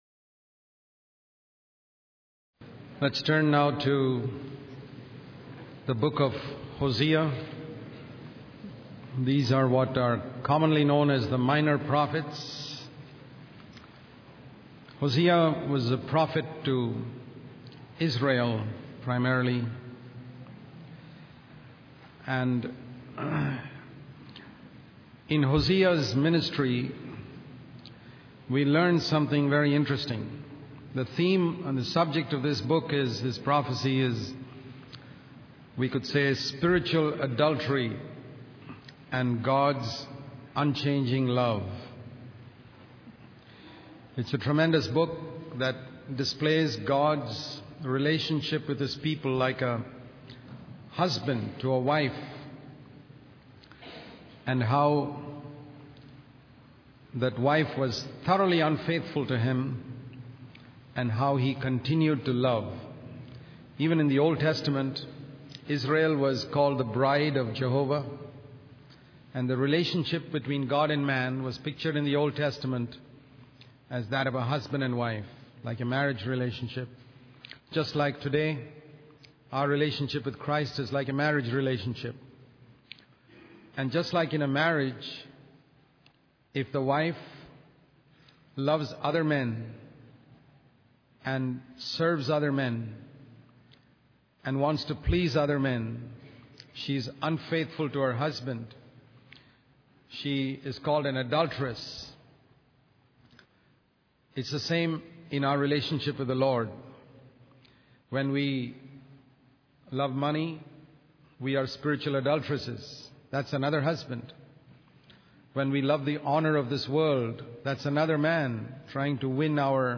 In this sermon, the preacher emphasizes the need for the church to come together in fasting, seeking God, and repentance. He warns that there is a lack of fruit in the church, both in character and ministry.